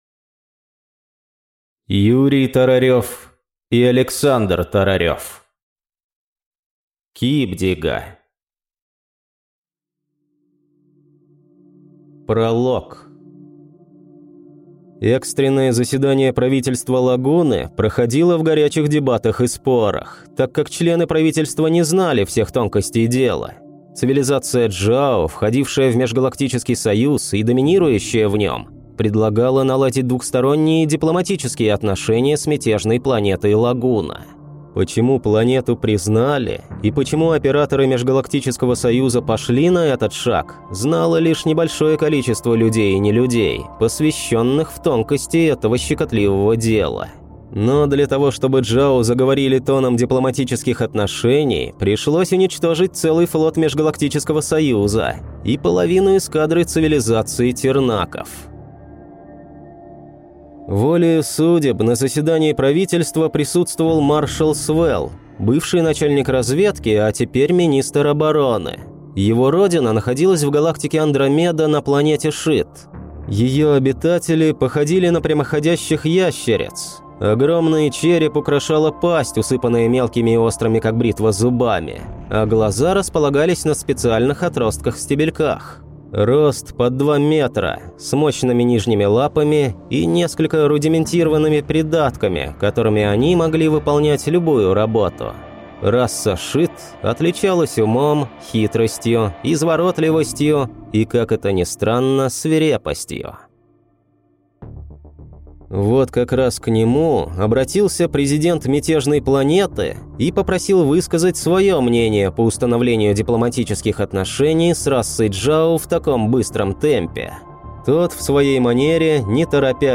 Аудиокнига КИБДИГО | Библиотека аудиокниг